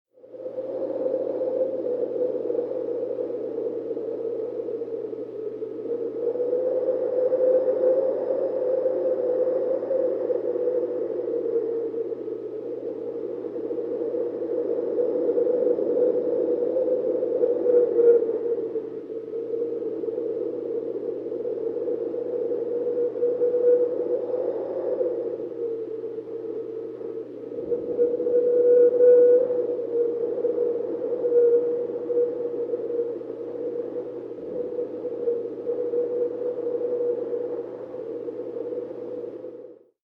Polar-Wind-Howling-Sound-Effect-for-editing-320k.mp3